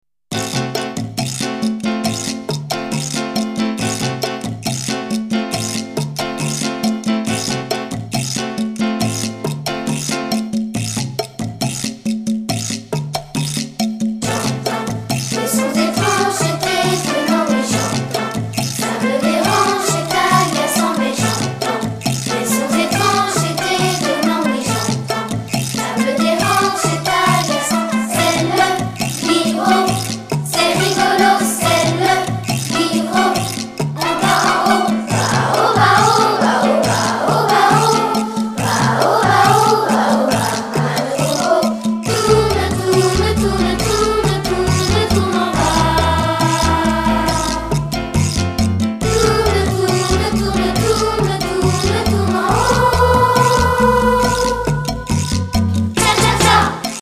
Musique inspirée de la salsa afro-cubaine.